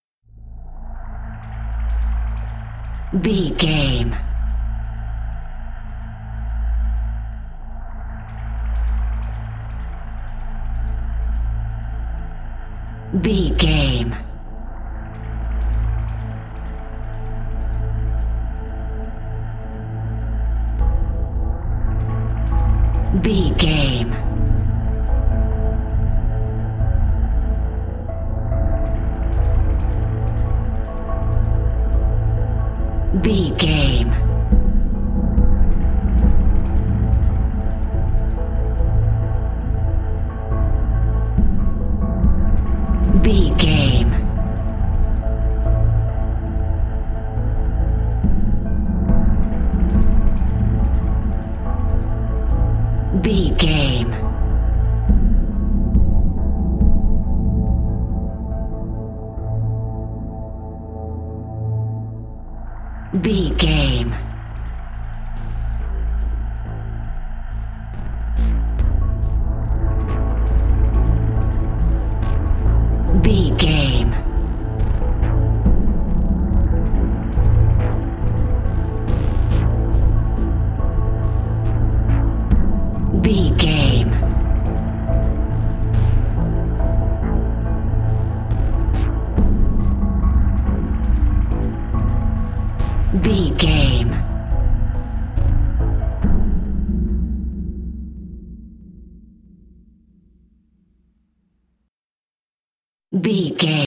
In-crescendo
Thriller
Aeolian/Minor
Slow
piano
synthesiser
ominous
dark
suspense
haunting
tense
creepy